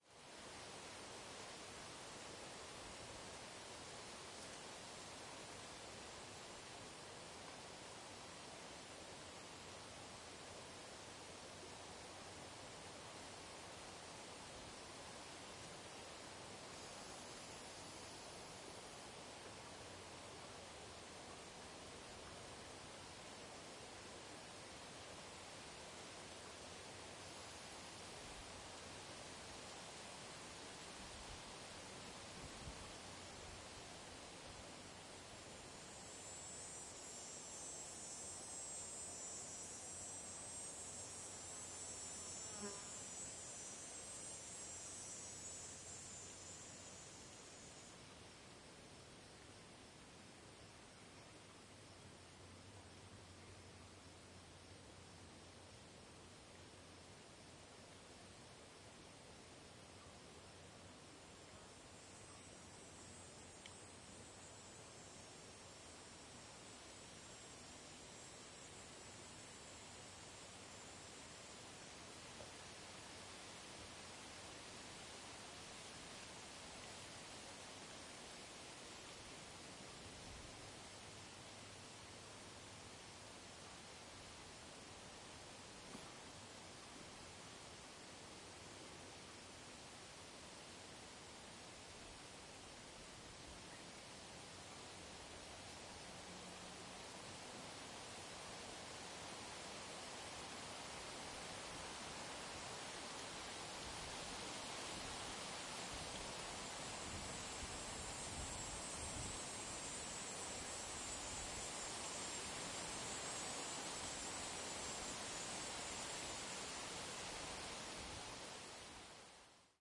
森林环境 蟋蟀 苍蝇
描述：记录环境森林声音：蟋蟀，鸟类，树木，苍蝇。在MS原始设置上使用H2N变焦记录器记录。解码为立体声。
标签： 农村 森林 蟋蟀 森林 现场录音 安大略省 苍蝇 加拿大
声道立体声